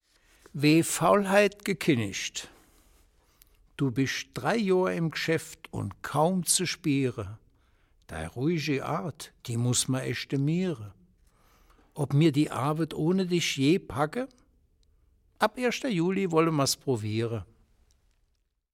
Eine Auswahl an Gedichten von Heinrich Kraus, gelesen